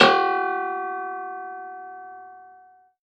53x-pno02-G2.wav